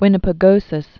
(wĭnə-pĭ-gōsĭs), Lake